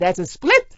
gutterball-3/Gutterball 3/Commentators/Maria/maria_thatsasplit.wav at d85c54a4fee968805d299a4c517f7bf9c071d4b9
maria_thatsasplit.wav